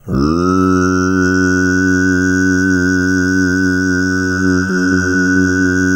TUV3 DRONE11.wav